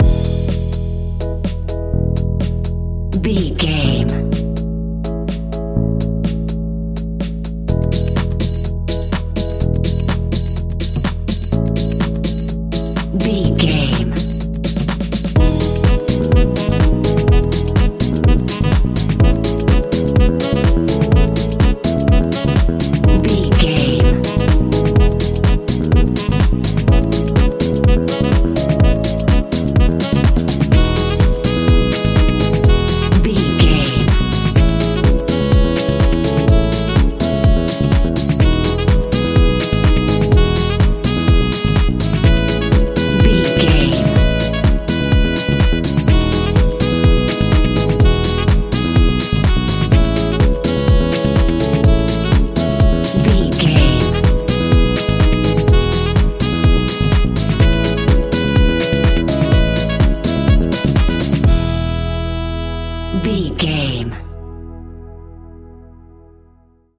Ionian/Major
uplifting
energetic
bouncy
bass guitar
saxophone
electric piano
drum machine
disco
groovy
upbeat
funky guitar
synth bass